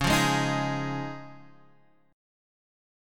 C#add9 chord